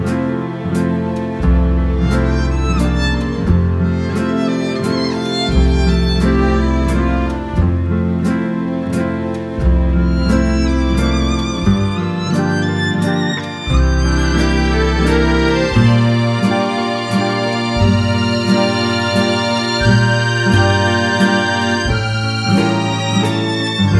no Backing Vocals Crooners 2:43 Buy £1.50